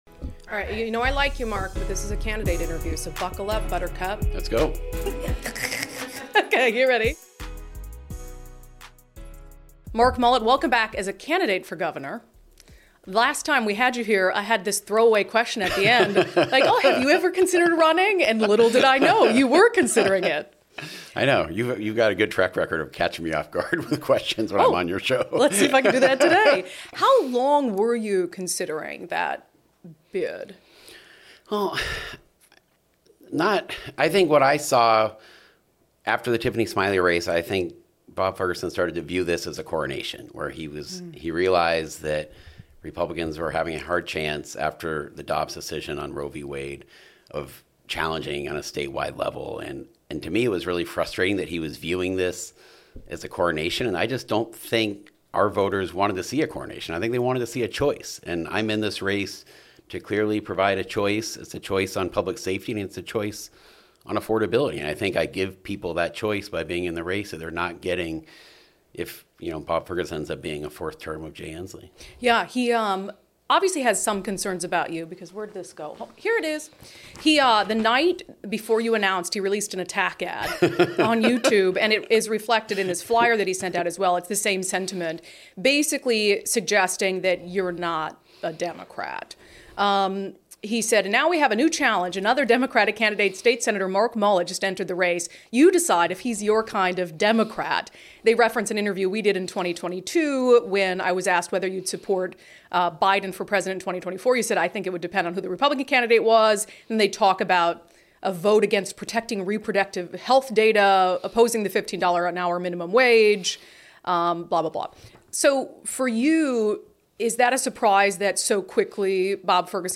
INTERVIEW: Sen. Mark Mullet (D) on his campaign for governor